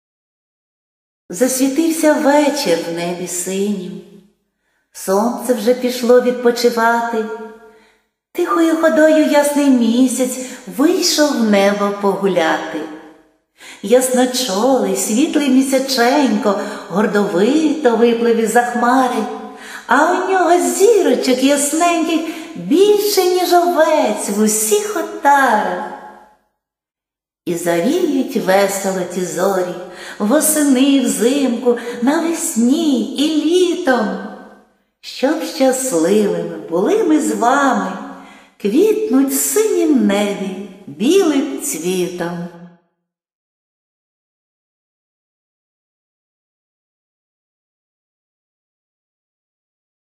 і дикція, і вірш 12